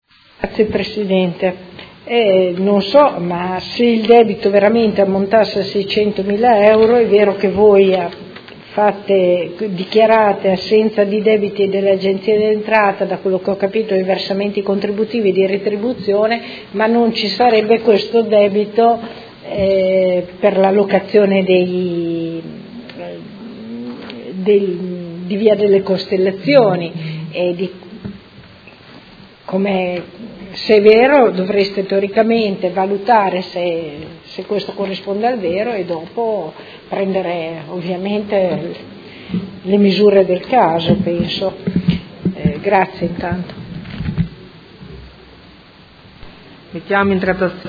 Seduta del 21/02/2019. Conclude interrogazione della Consigliera Santoro (Lega Nord) avente per oggetto: Fondi a Caleidos